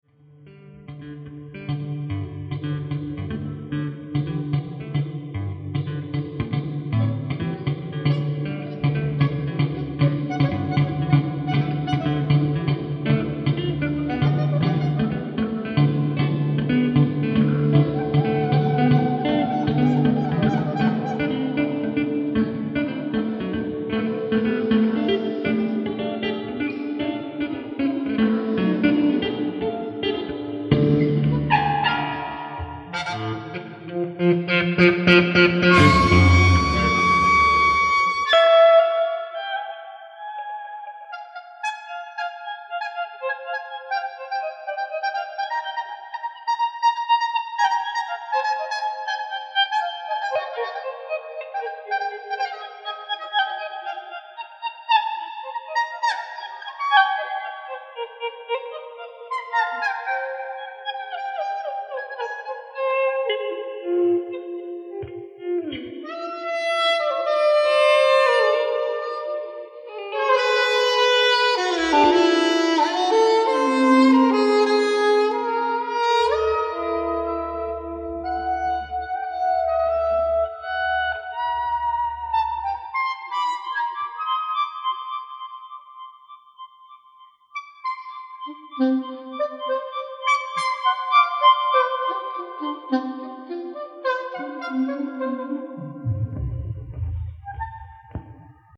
地球外イメージかき立てる、霊感山勘みなぎる謎めいた即興空間。
キーワード：霊性　地球外　即興